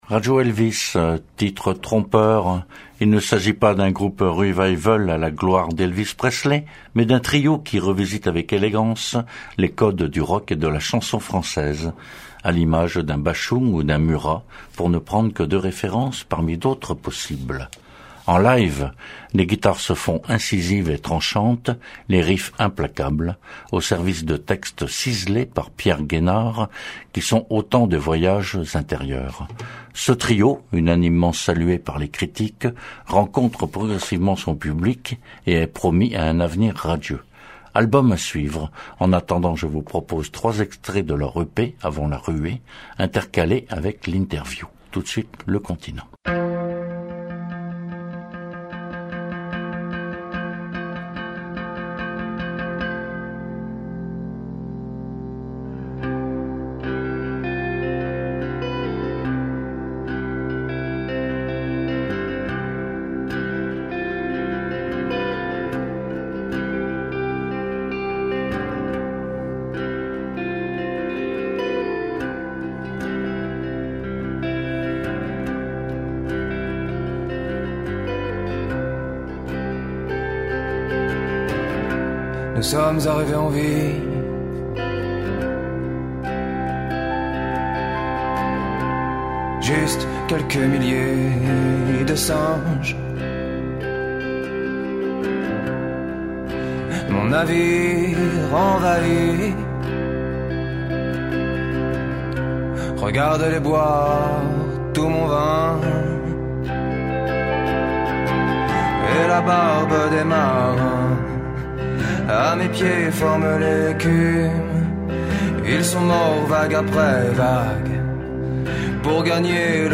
2ème partie : Radio Elvis – La Cordonnerie/Cité de la musique à Romans le 16 avril 2015